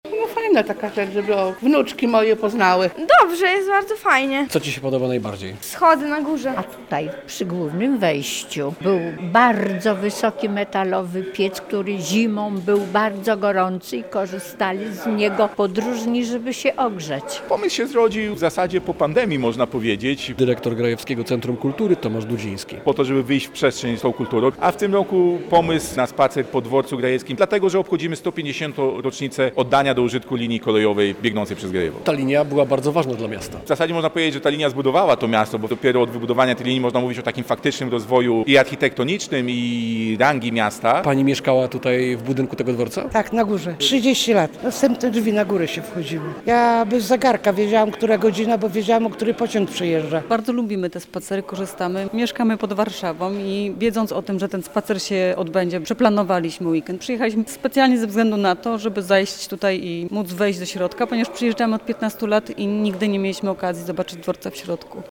Spacer historyczny po grajewskim dworcu kolejowym - relacja